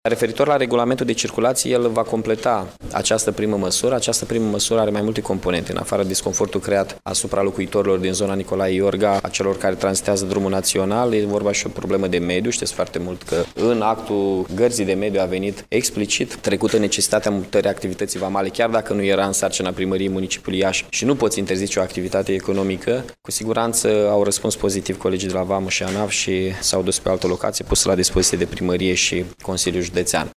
Primarul interimar al Iașului Mihai Chirica, a declarat că Biroul vamal de la Direcția Regionala Iași se mută până la sfârșitul acestei săptămâni pe amplasamentul de la capătul cartierului CUG, in apropiere de FORTUS.